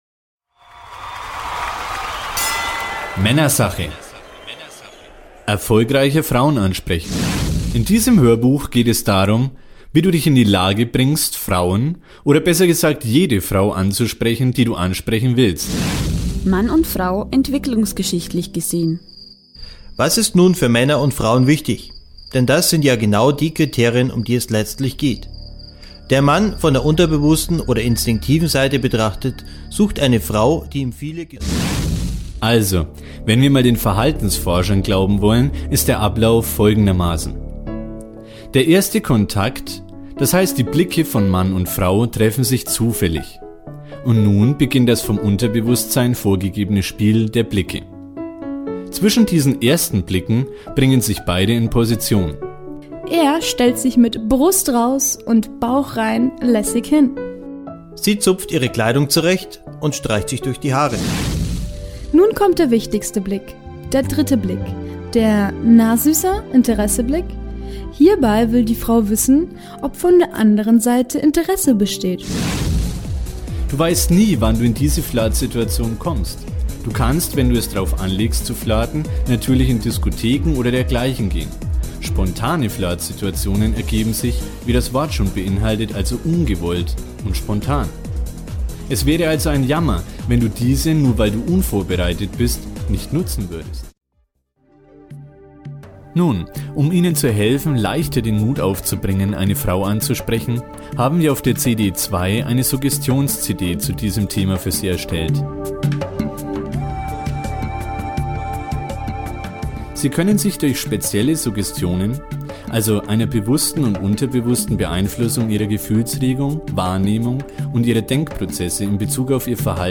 Audio-Ratgeber und Suggestions-CD